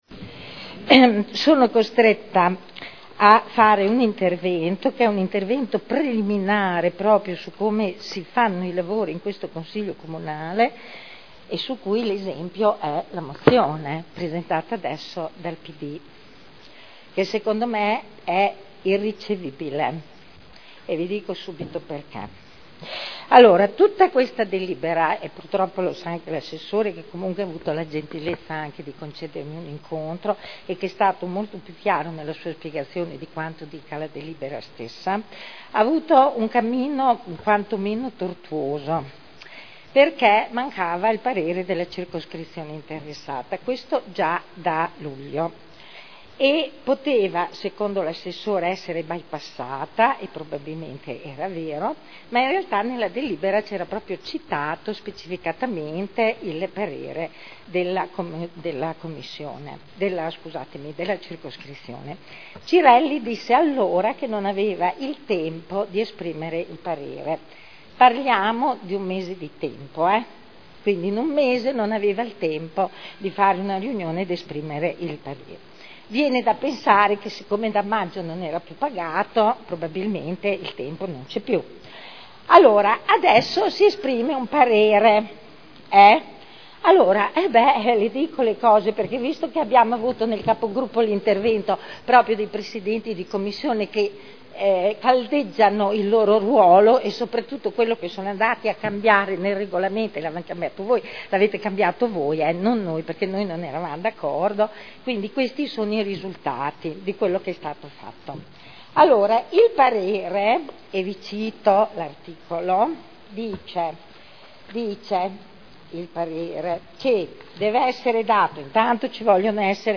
Seduta del 24/09/2012 Dibattito su Delibera Zona elementare 280 Area 01 e Area 06 – Via Giardini – Variante al POC-RUE con valore ed effetti di Piano Urbanistico Attuativo (PUA) e Mozione prot. 111618